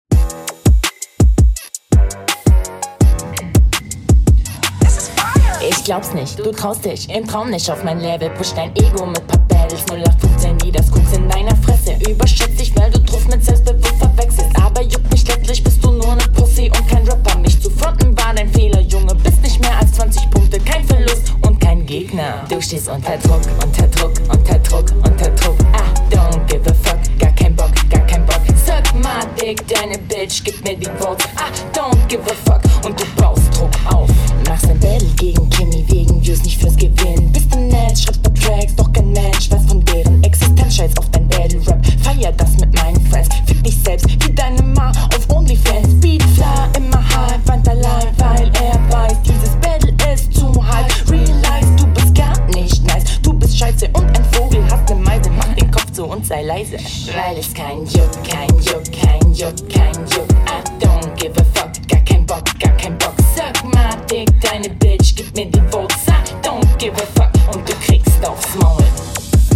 Flow: bisschen cooler geflowed als dein gegner, hat nen netten groove Text: nichts besonderes Soundqualität: …